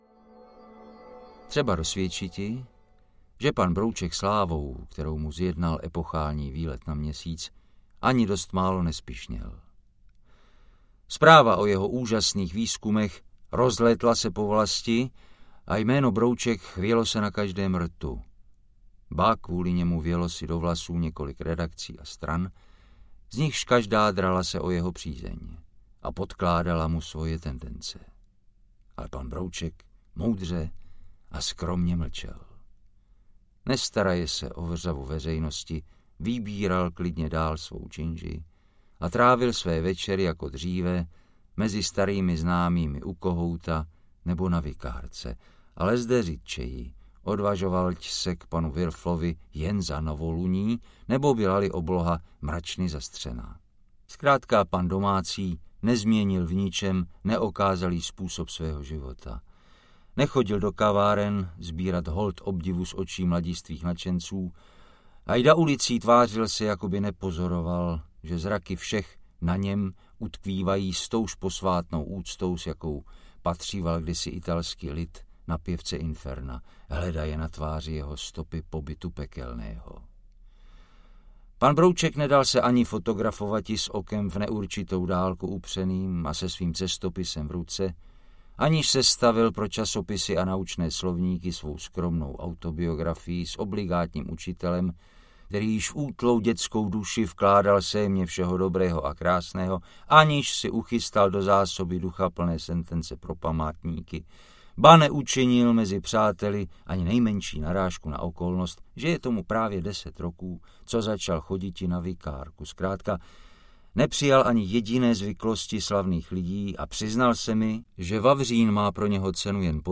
Ukázka z knihy
• InterpretJiří Štědroň